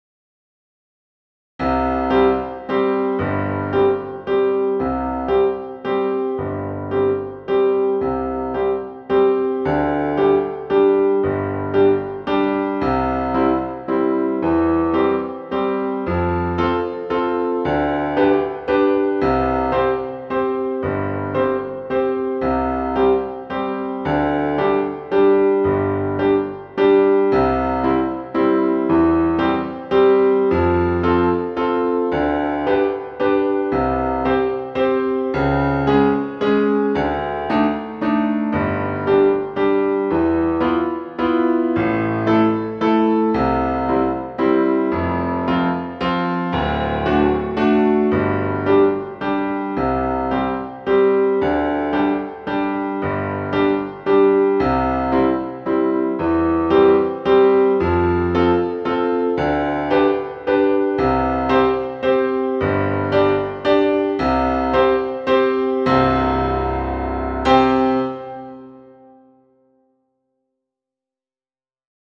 Klavierpart in langsamem Übungs-Tempo